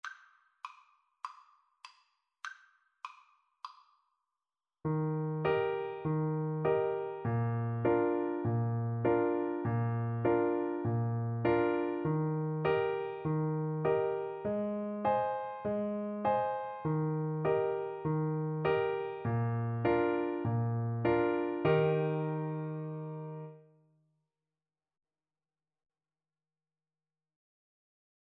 Alto Saxophone
With a swing!